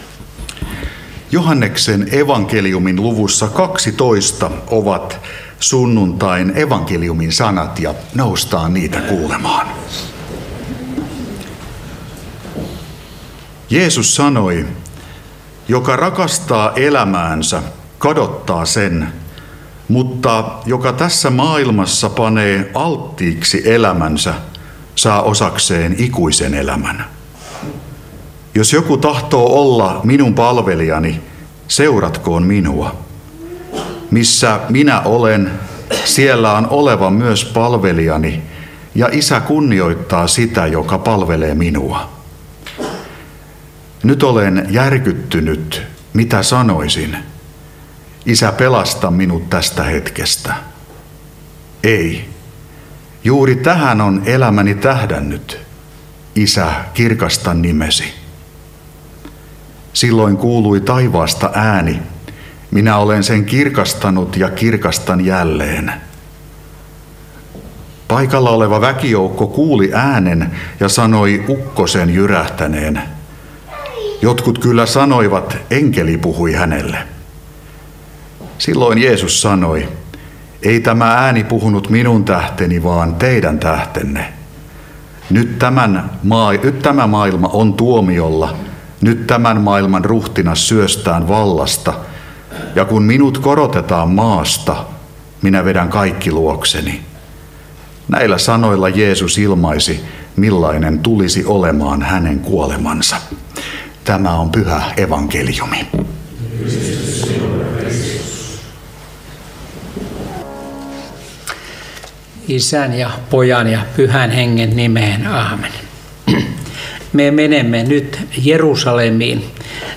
saarna Karkussa laskiaissunnuntaina Tekstinä Joh. 12:25–33